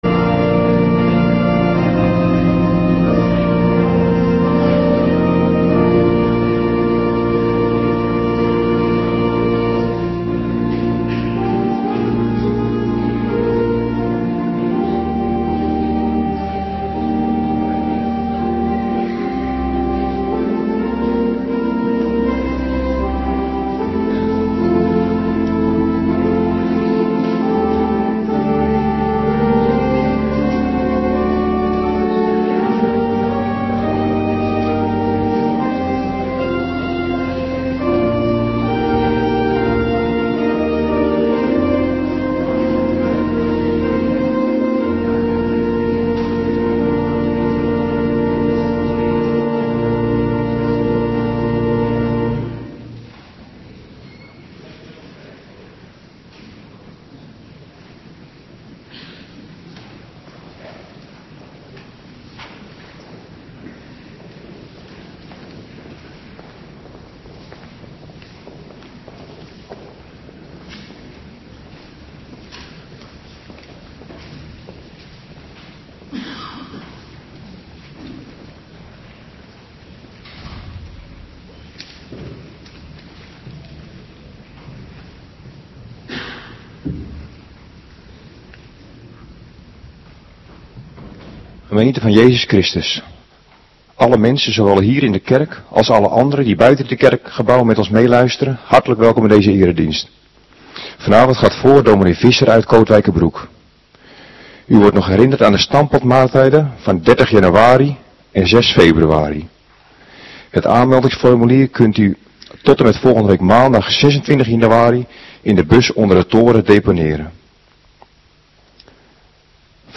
Avonddienst 18 januari 2026